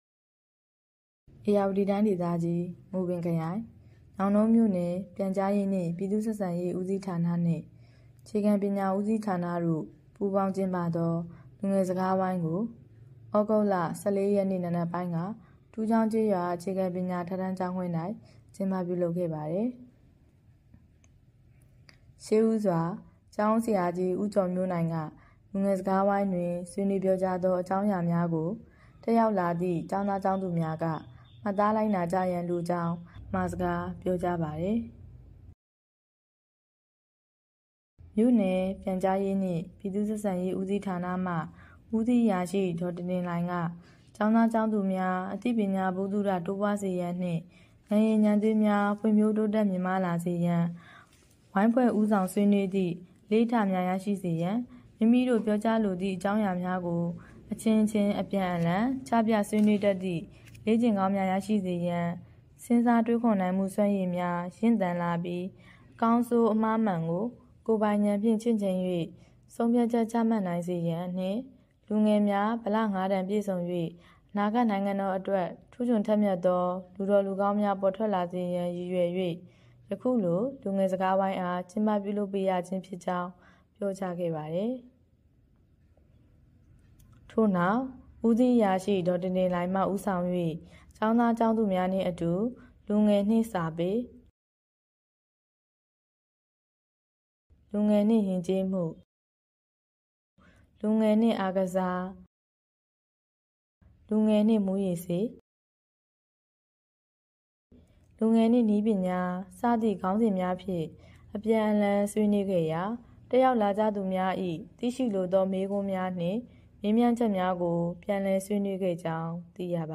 တူးချောင်းကျေးရွာ အခြေခံပညာအထက်တန်းကျောင်း(ခွဲ)တွင် လူငယ်စကားဝိုင်းကျင်းပ ညောင်တုန်း သြဂုတ် ၁၆ ပေးပို့သူ - မြို့နယ်ပြန်/ဆက်